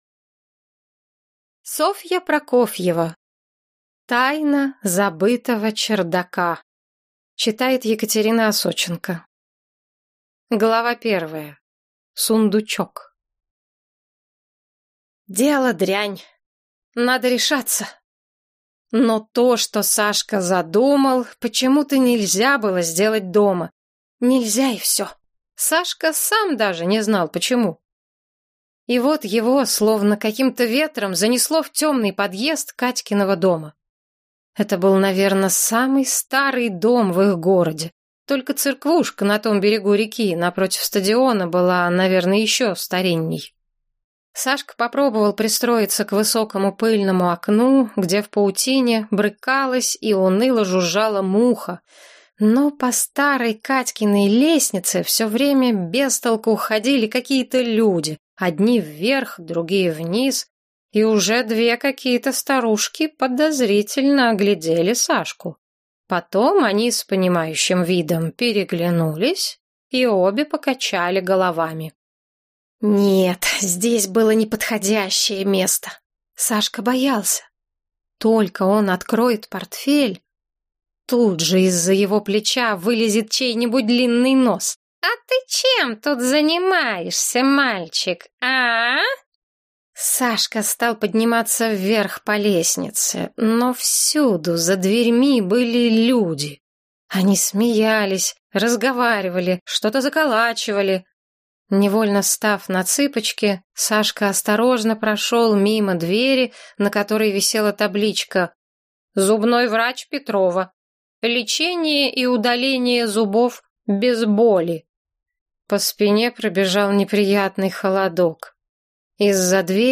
Аудиокнига Тайна забытого чердака | Библиотека аудиокниг